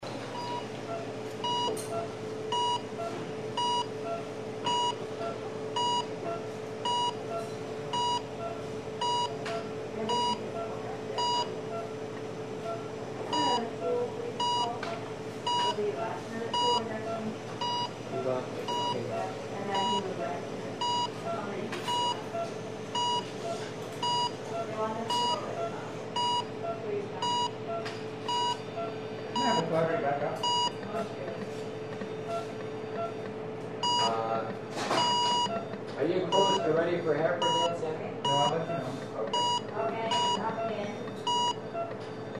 10 Звуки кардиомониторы в больнице во время операции
10-Звуки-кардиомониторы-в-больнице-во-время-операции.mp3